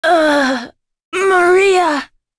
voices / heroes / en
Morrah-Vox_Dead_b.wav